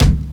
Kicks
Medicated Kick 24.wav